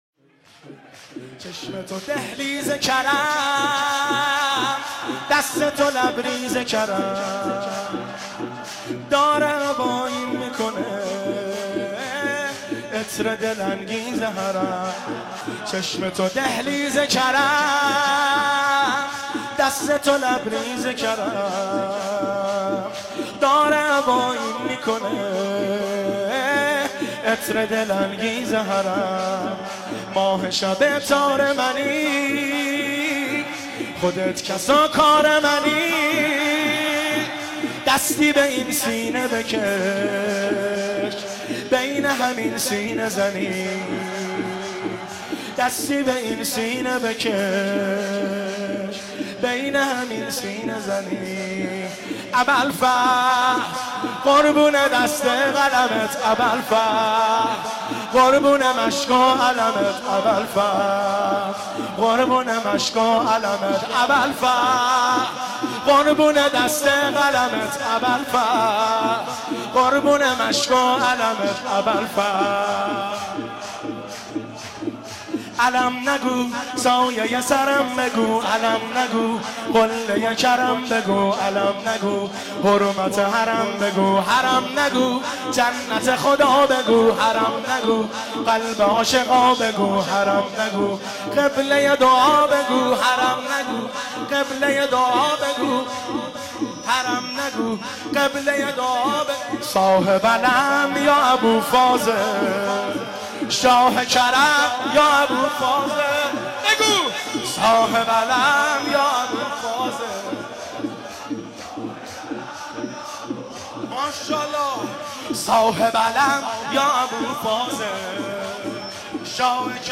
چشم تو دهلیز کرم ، دست تو لبریز کرم شور - شب 20 رمضان المبارک 1394 هیئت بین الحرمین طهران